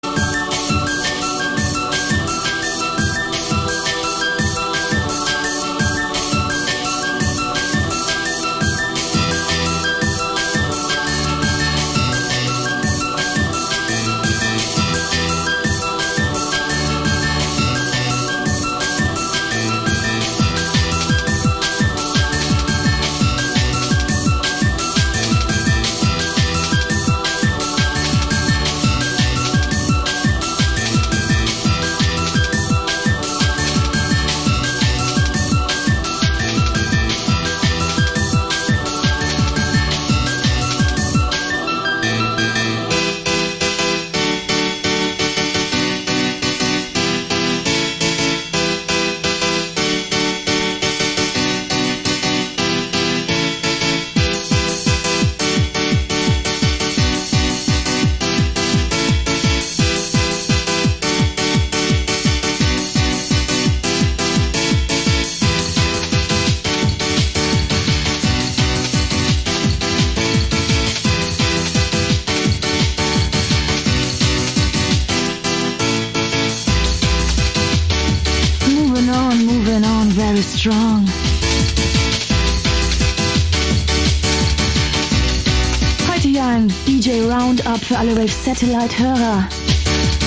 Zná někdo tyto Rave hudební skladby?